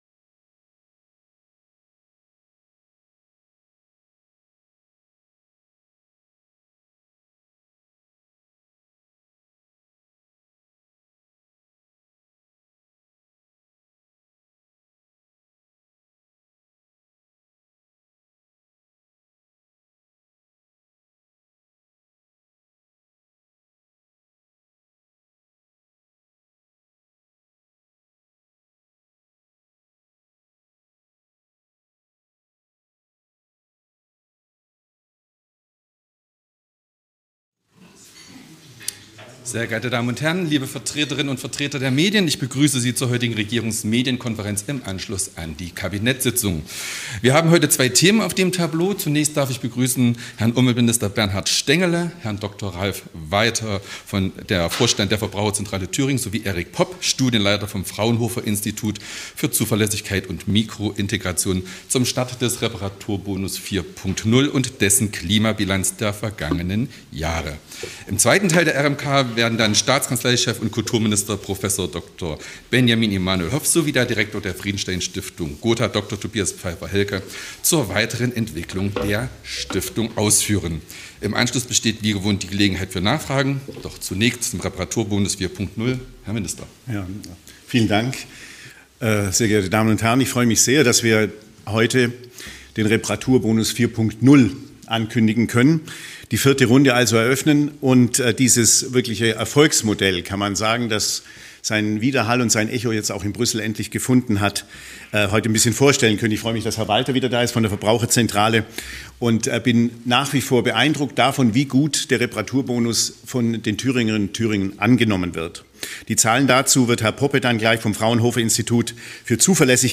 Thüringer Reparaturbonus 4.0 startet zum 15. Mai ~ Regierungsmedienkonferenz des Freistaats Thüringen Podcast